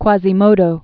(kwäzē-mōdō), Salvatore 1901-1968.